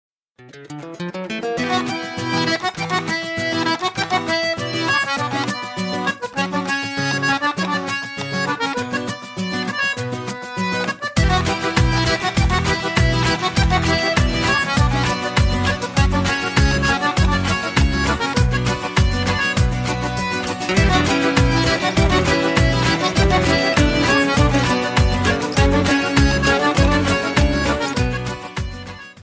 Рингтоны Без Слов
Шансон Рингтоны